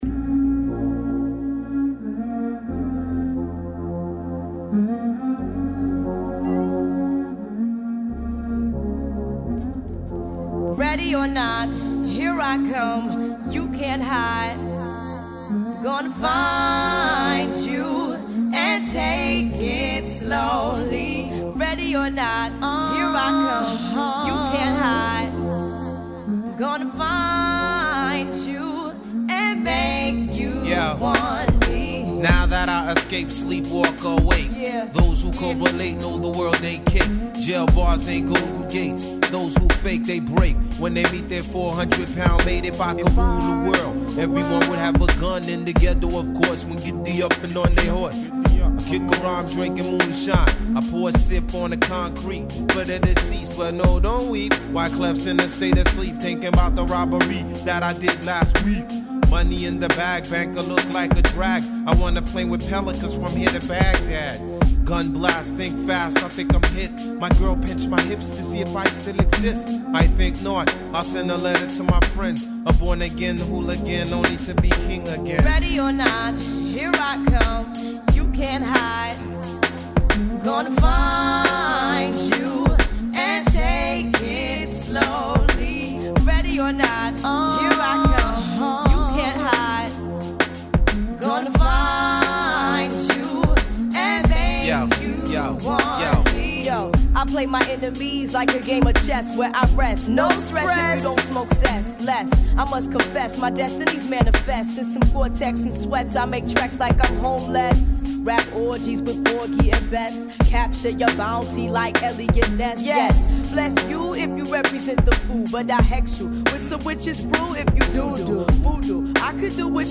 an intriguing blend of jazz-rap, R&B, and reggae